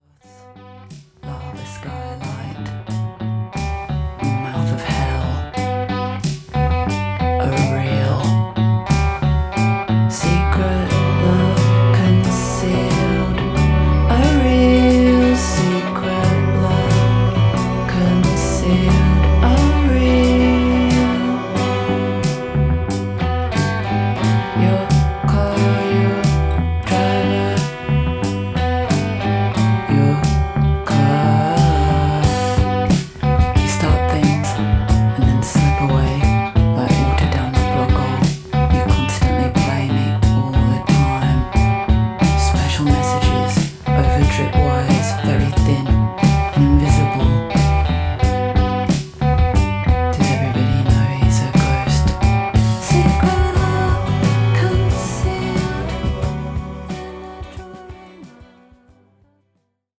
クールでスリリングなアルバムです。